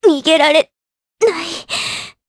Artemia-Vox_Dead_jp.wav